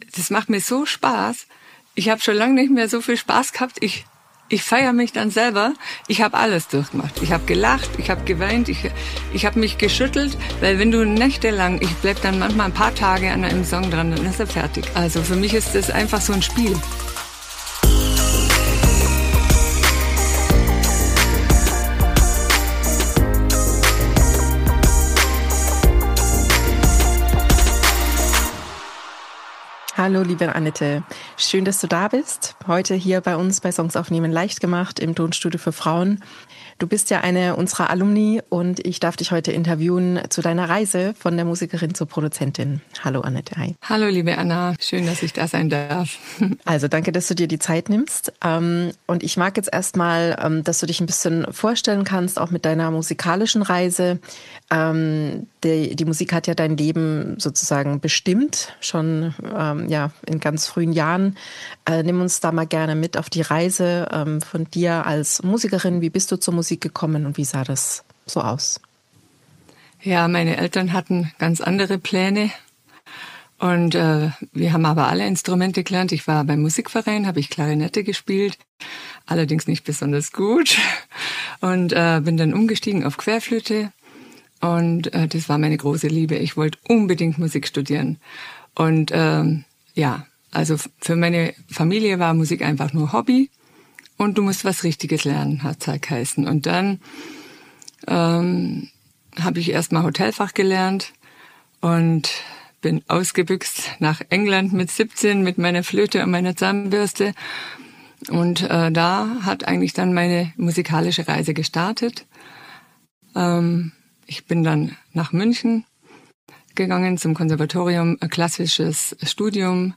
im Interview ~ Songs aufnehmen leicht gemacht von Tonstudio für Frauen Podcast